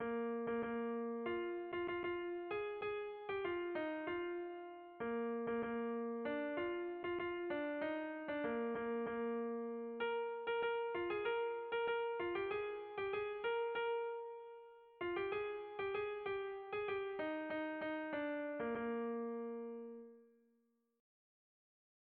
Erromantzea
Zortziko txikia (hg) / Lau puntuko txikia (ip)
A-A2-B-C